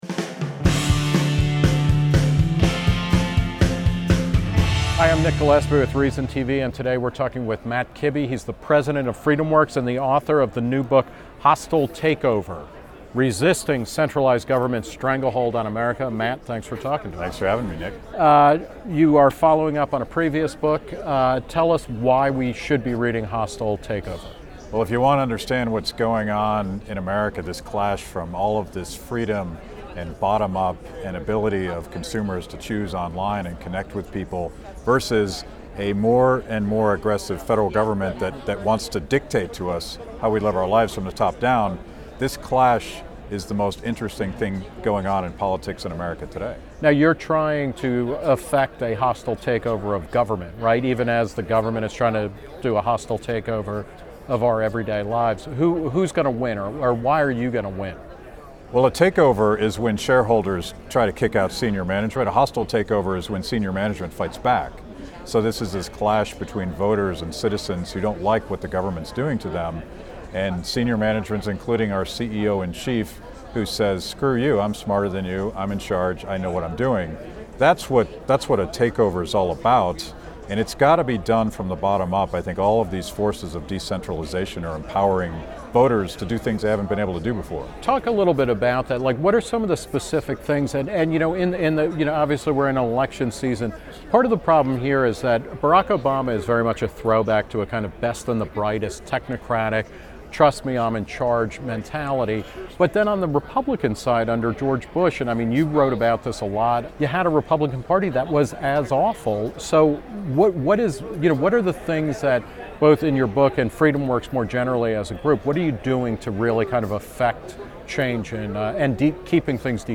Reason's Nick Gillespie caught up with Kibbe at FreedomFest to discuss the book, the power of the Tea Party, and the marquee races to watch in 2012.